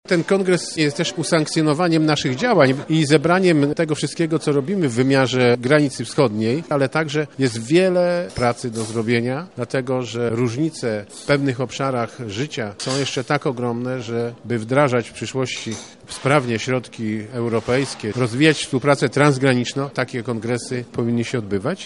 Fundacje i stowarzyszenia mogą działać w obszarach niedostępnych dla samorządów – mówi prezydent Krzysztof Żuk.